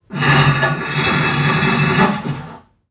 NPC_Creatures_Vocalisations_Robothead [33].wav